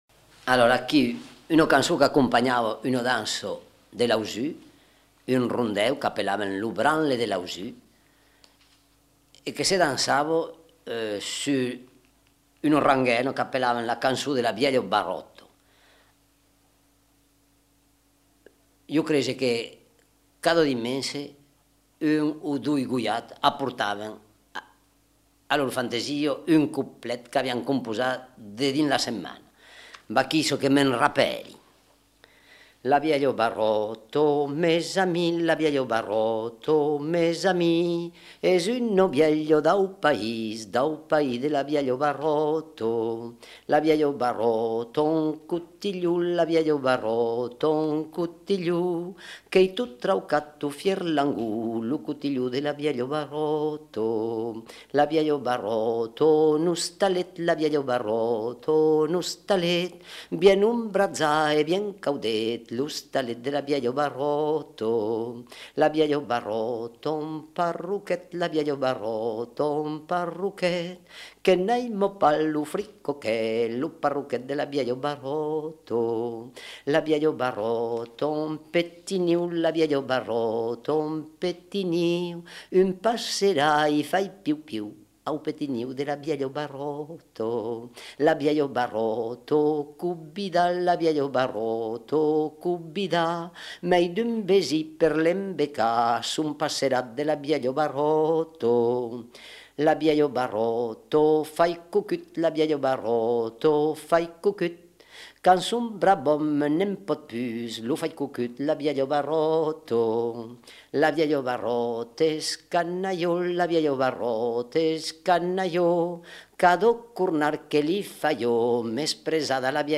Lieu : Lauzun
Genre : chant
Effectif : 1
Type de voix : voix d'homme
Production du son : chanté
Danse : rondeau